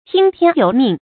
注音：ㄊㄧㄥ ㄊㄧㄢ ㄧㄡˊ ㄇㄧㄥˋ
聽天由命的讀法